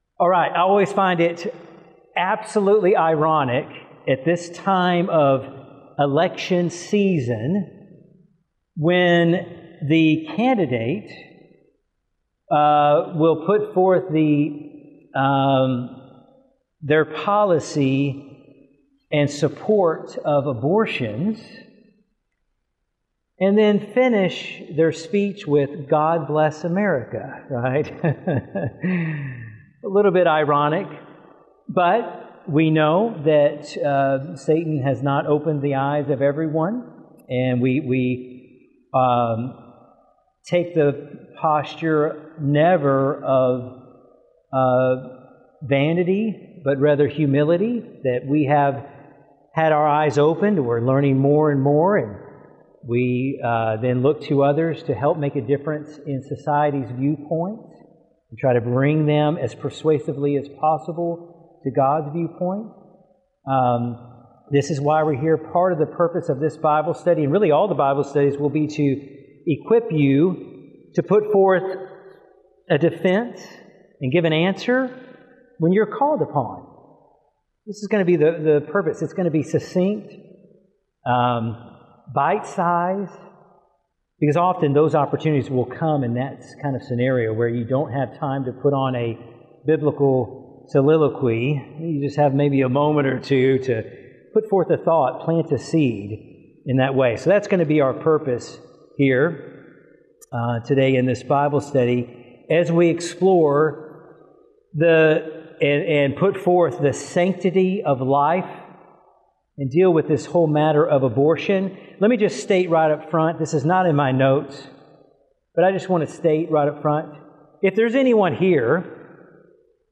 In the main part of the study he asks questions about the abortion issue taking answers from the audience. He ends the study by showing us where the rights people claim justify abortion came from.